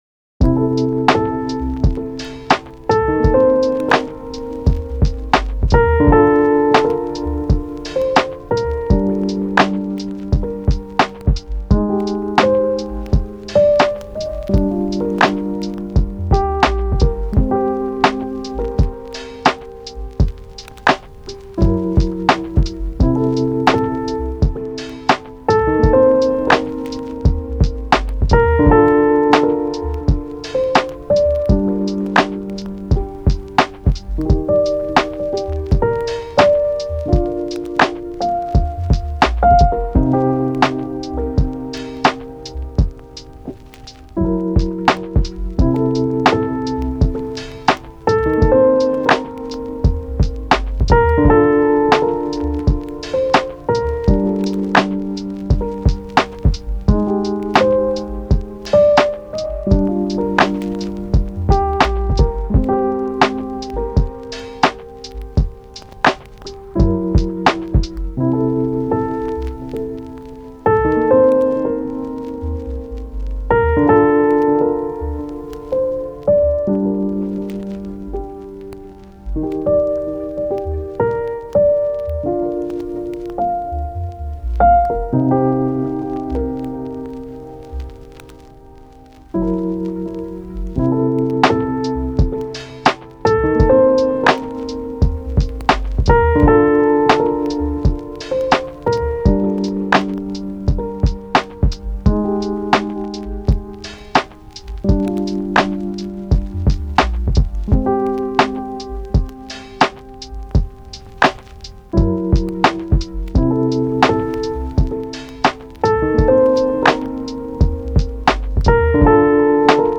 フリーBGM
チル・穏やか
メロウ・切ない